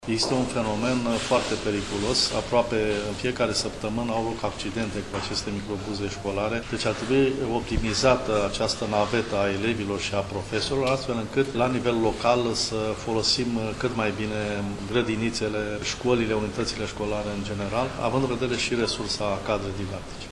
Demnitarul, aflat în vizită la o şcoală din Dâmboviţa, a dat asigurări că nu vor fi închise unităţi de învăţământ din cauza numărului mic de elevi.
Pavel Năstase şi-a manifestat şi îngrijorarea faţă de elevii care fac naveta cu microbuzele şi îşi riscă astfel viaţa: